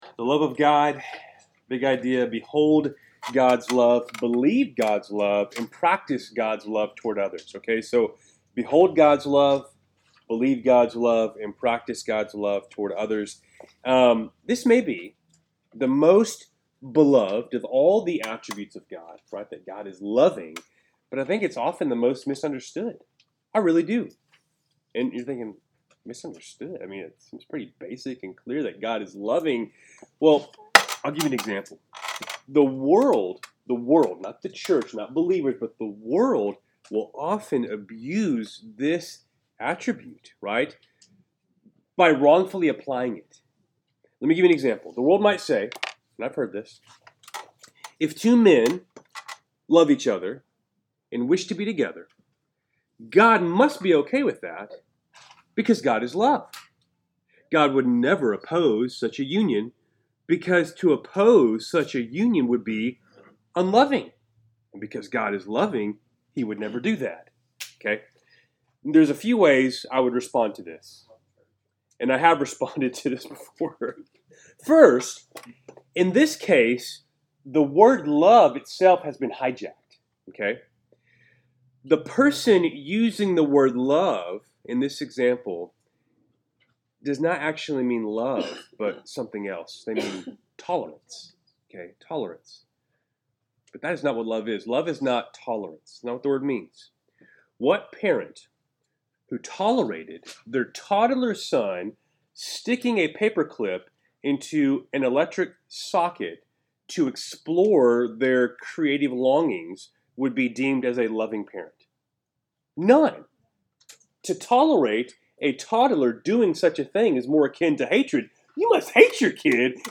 Wednesday Night Bible Study, February 5, 2025